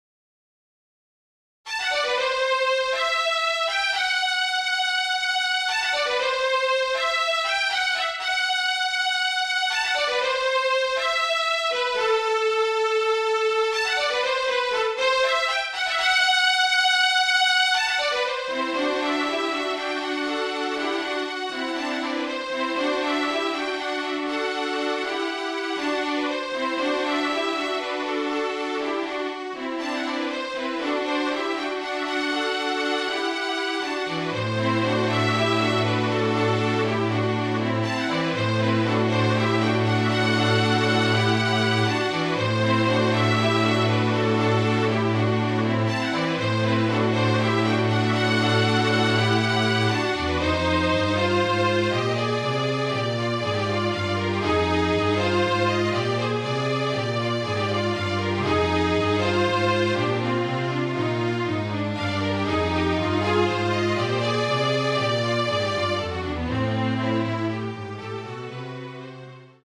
FLUTE TRIO
Flute, Violin and Cello (or Two Violins and Cello)
MIDI